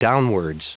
Prononciation du mot downwards en anglais (fichier audio)
Prononciation du mot : downwards